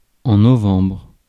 Ääntäminen
Ääntäminen France: IPA: [nɔ.vɑ̃bʁ] Haettu sana löytyi näillä lähdekielillä: ranska Käännös 1. november Suku: m .